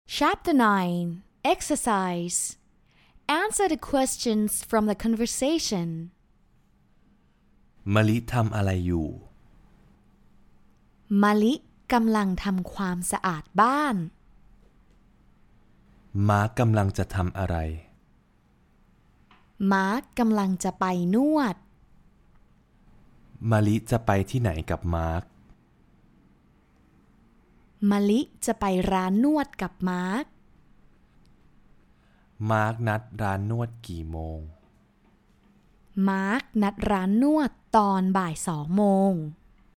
Conversation: What are you going to do?